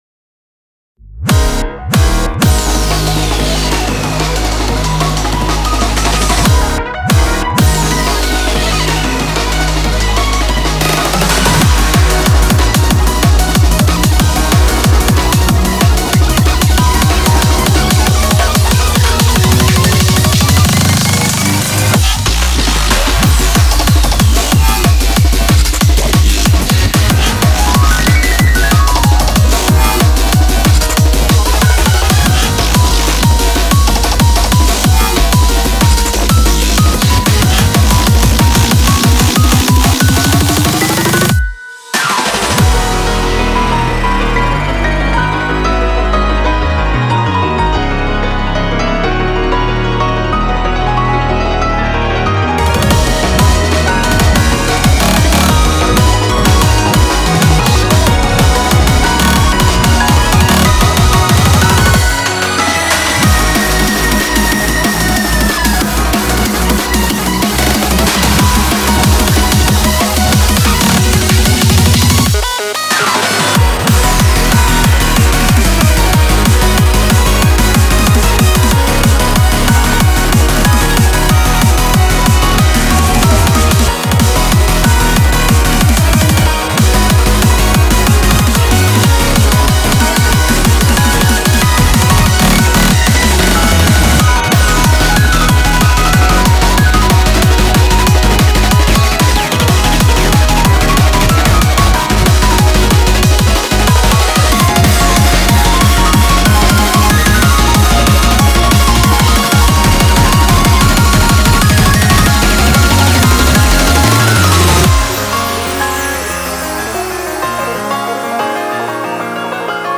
BPM93-186